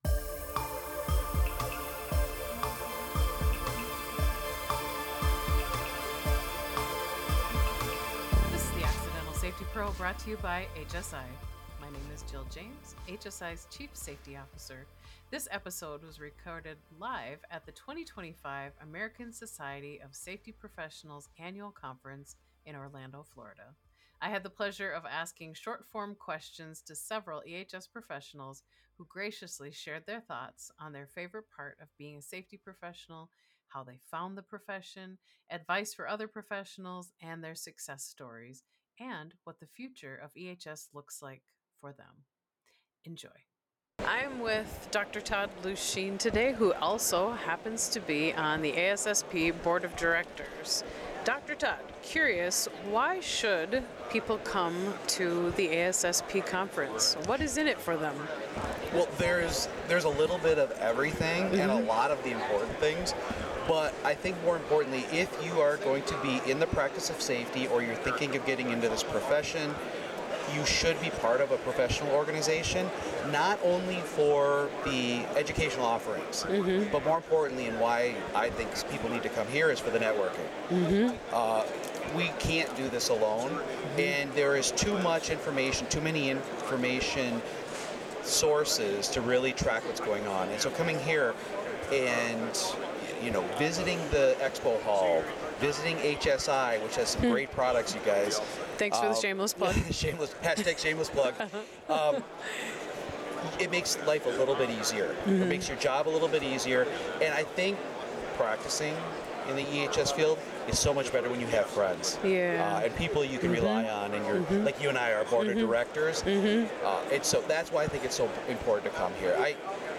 Live From ASSP Safety 2025 - HSI